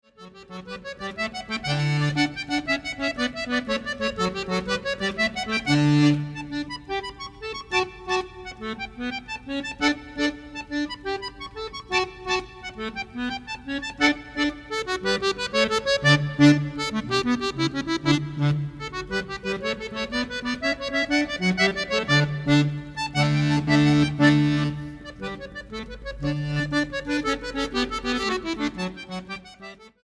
acordeón clásico
vihuela y guitarra renacentistas
percusiones
guitarra española y percusiones